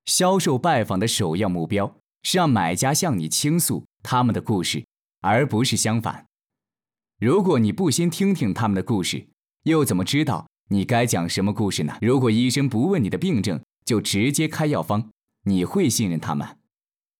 Chinese_Male_049VoiceArtist_2Hours_High_Quality_Voice_Dataset